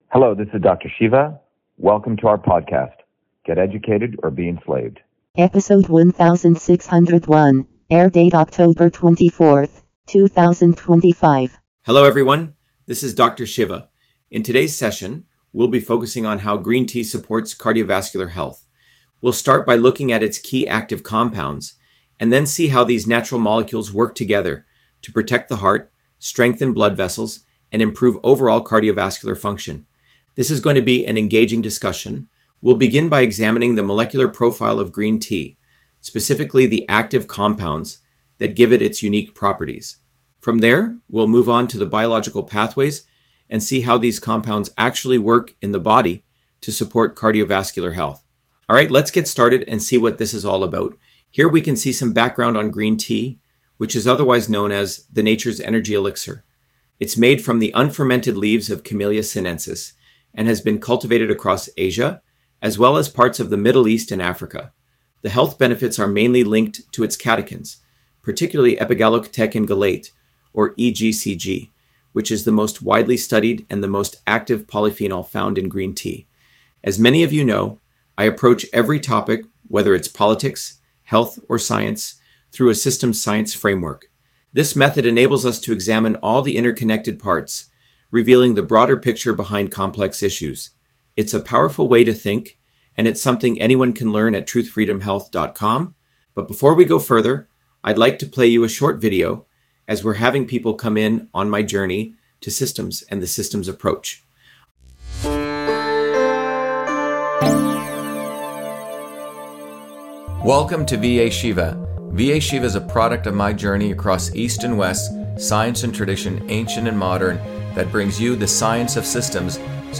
In this interview, Dr.SHIVA Ayyadurai, MIT PhD, Inventor of Email, Scientist, Engineer and Candidate for President, Talks about Green Tea on Cardiovascular Health: A Whole Systems Approach